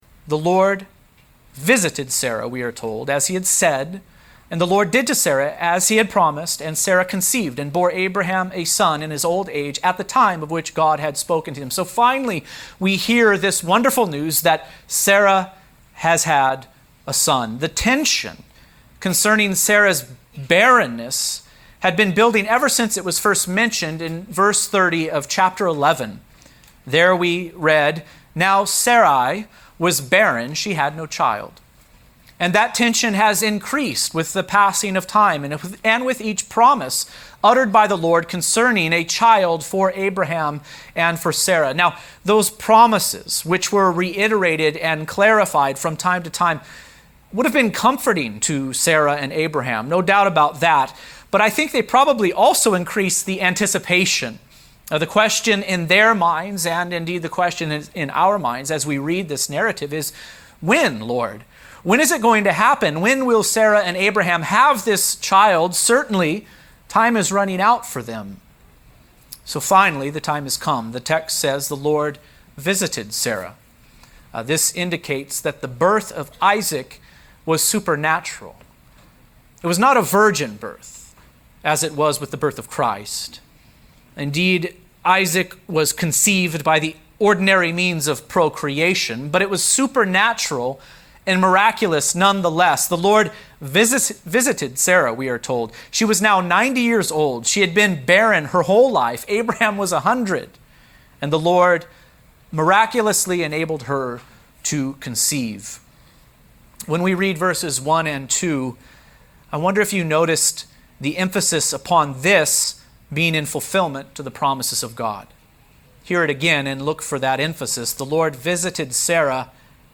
Promises Fulfilled, Isaac | SermonAudio Broadcaster is Live View the Live Stream Share this sermon Disabled by adblocker Copy URL Copied!